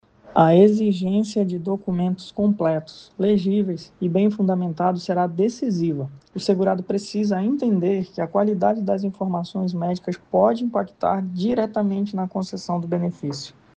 O advogado especialista em direito previdenciário